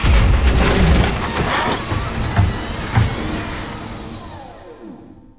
cargorepair.ogg